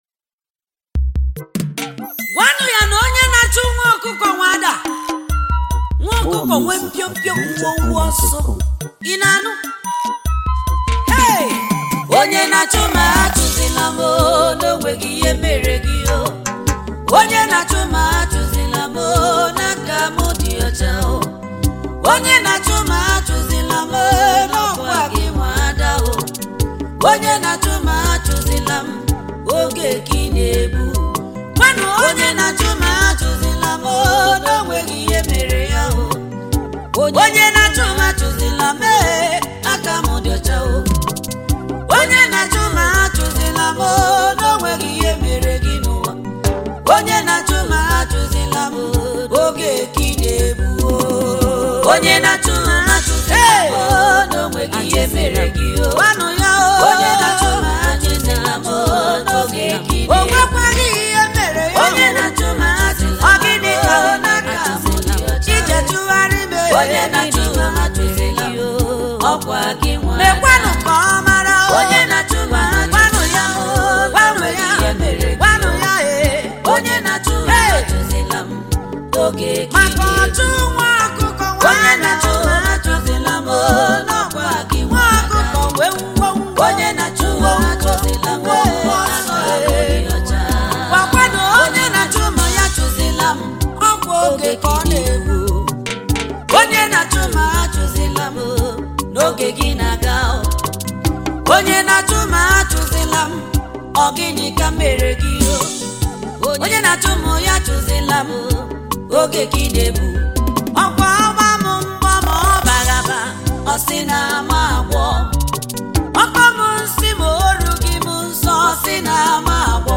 Igbo Gospel Music